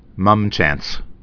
(mŭmchăns)